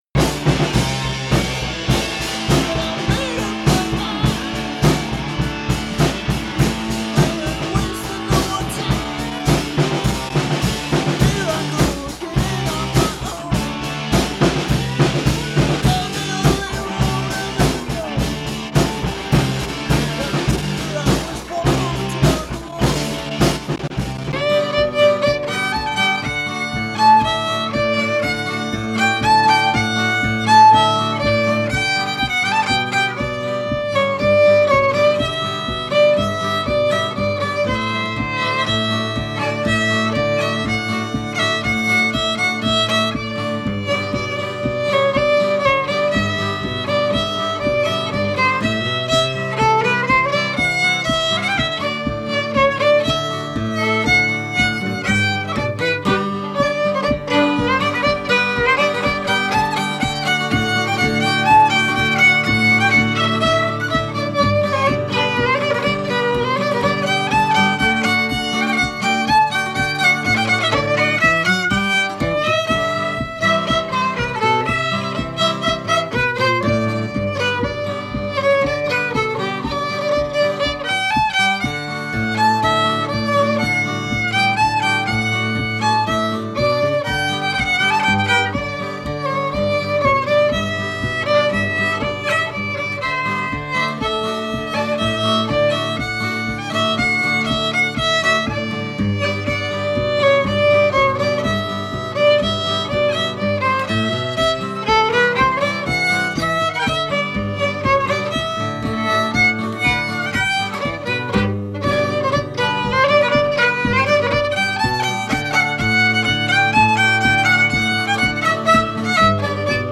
Fait partie de Local music performances